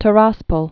(tə-räspəl)